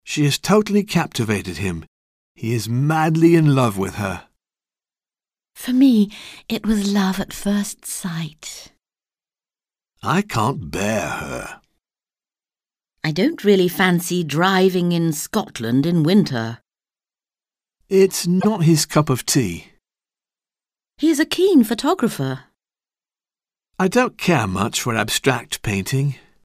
Un peu de conversation - Amour et haine